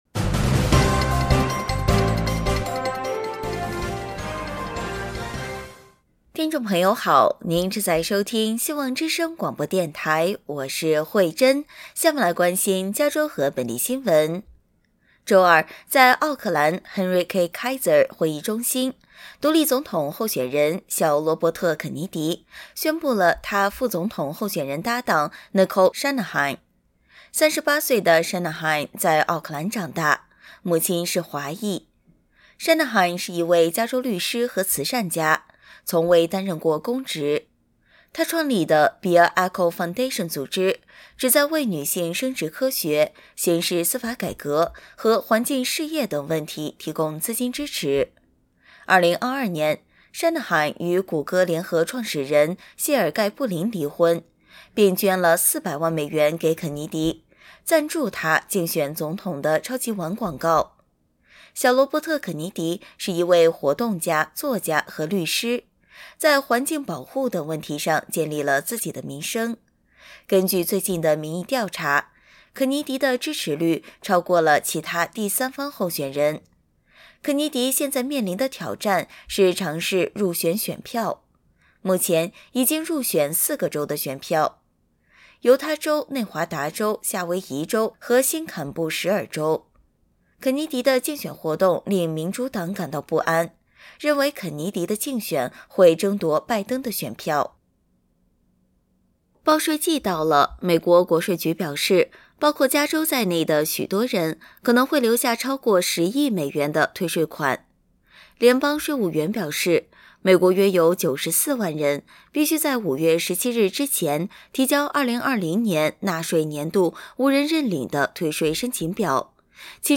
配音